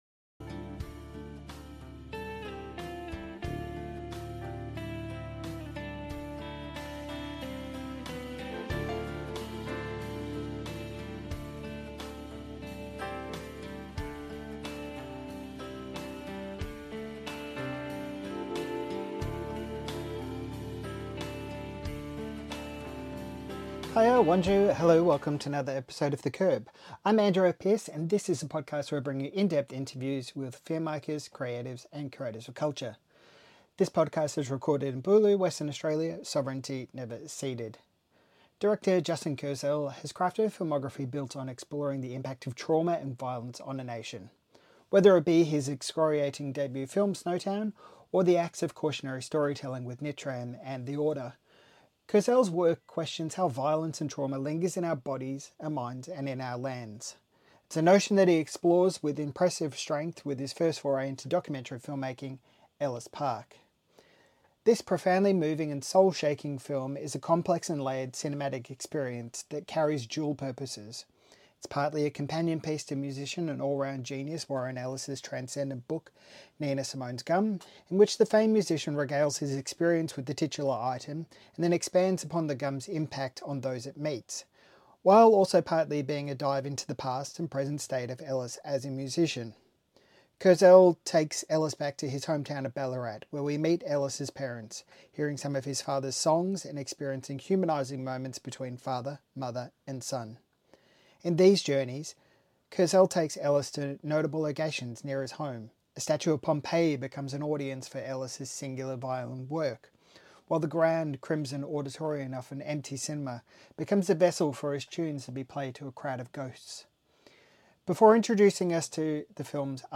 Sydney Film Festival Interview: Ellis Park director Justin Kurzel on being in the orbit of Warren Ellis - The Curb | Film and Culture